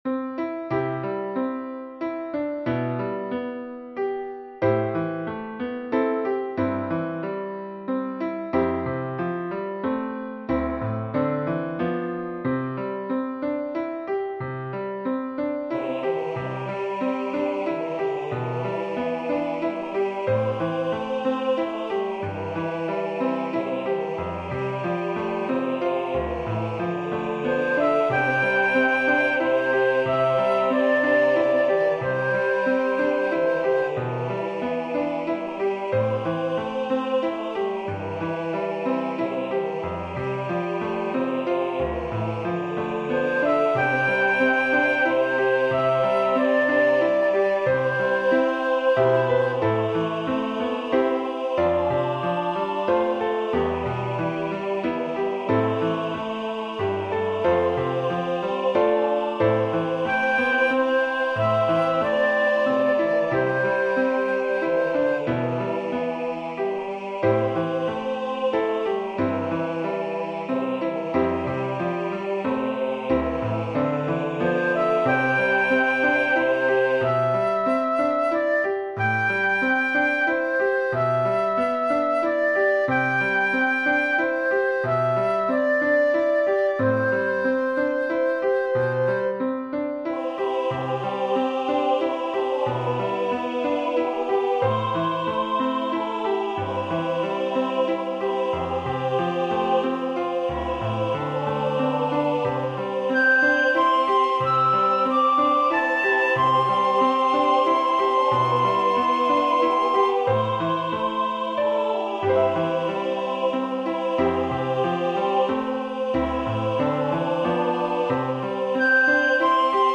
Voicing/Instrumentation: SAB